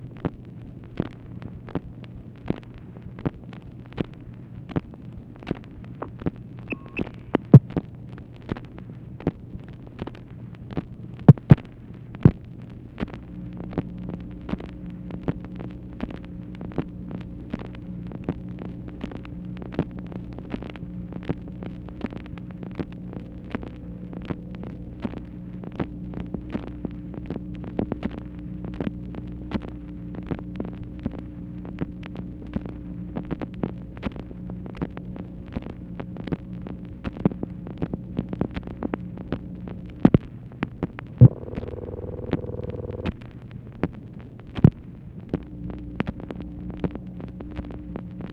MACHINE NOISE, September 26, 1968
Secret White House Tapes | Lyndon B. Johnson Presidency